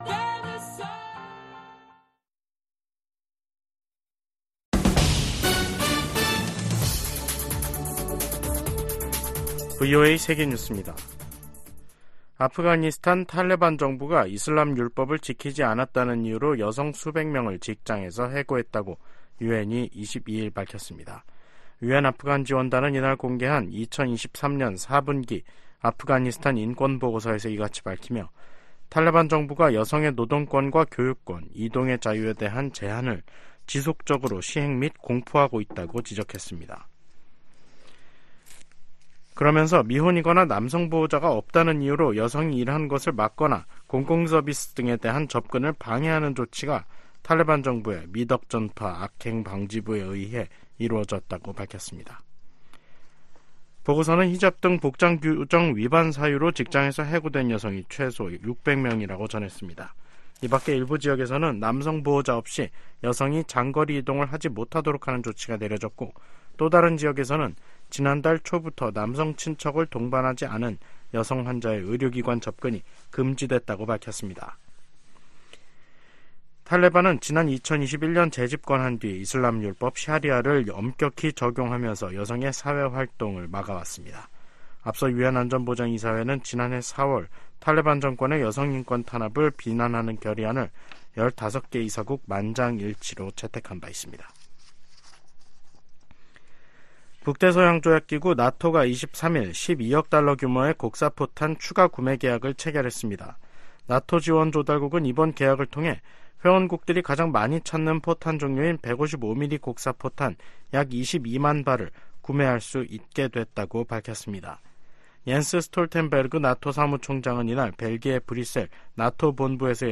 VOA 한국어 간판 뉴스 프로그램 '뉴스 투데이', 2024년 1월 23일 3부 방송입니다. 북한-러시아 군사협력은 역내 안정과 국제 비확산 체제를 약화시킨다고 유엔 주재 미국 차석대사가 지적했습니다. 백악관이 북한과 러시아의 무기 거래를 거론하며 우크라이나에 대한 지원의 필요성을 강조했습니다. 중국에 대한 보편적 정례 인권 검토(UPR)를 앞두고 유엔과 유럽연합(EU)이 탈북민 강제 북송 중단을 중국에 촉구했습니다.